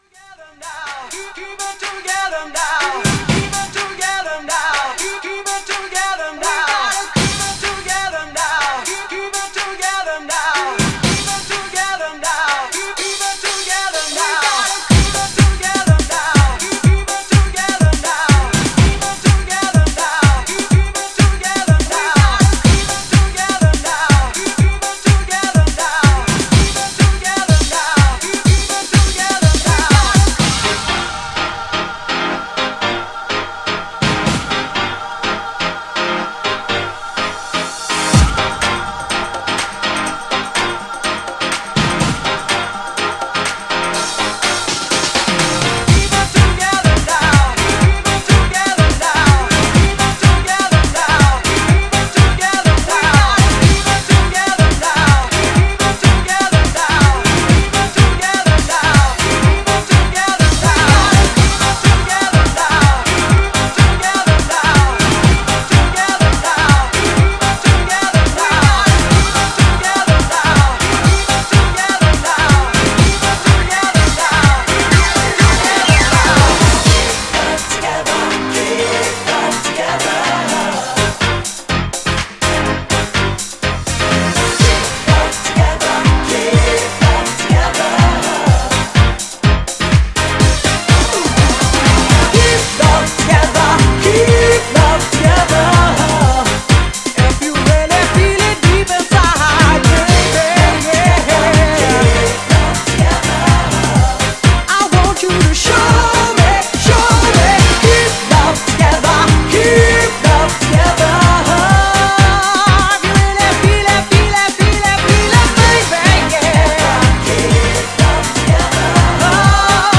HOUSE CLASSIC!
House